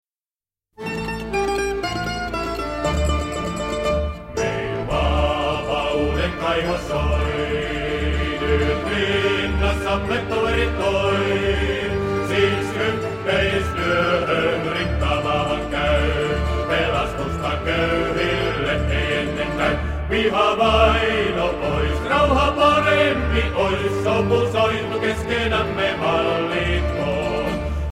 kontrabasso
klarinetti
mandoliini
kitara